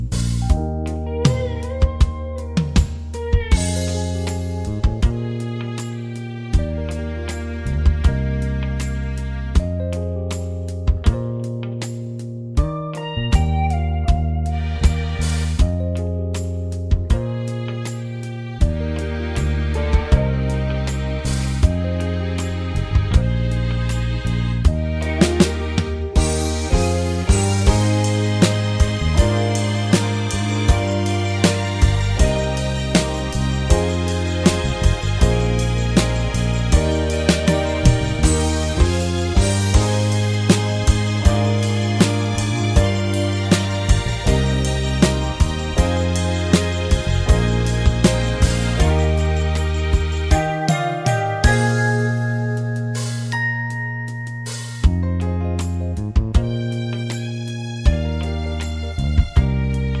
no Backing Vocals Disco 4:14 Buy £1.50